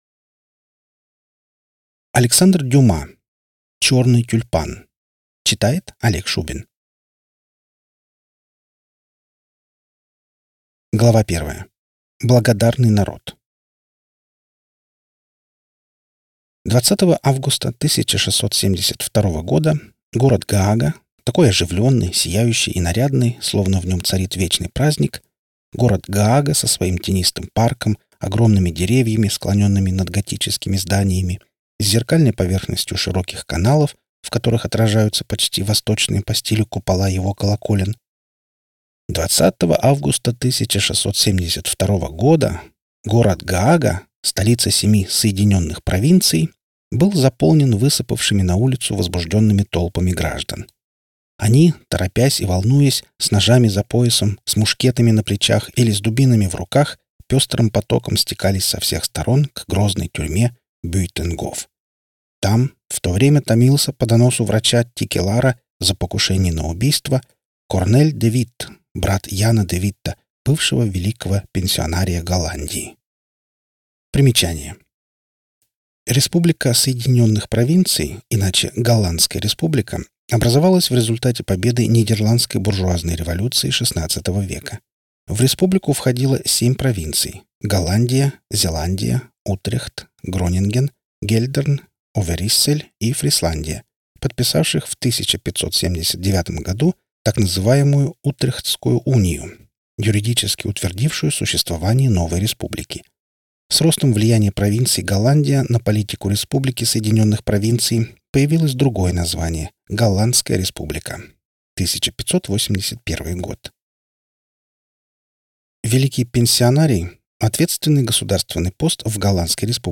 Аудиокнига Черный тюльпан - купить, скачать и слушать онлайн | КнигоПоиск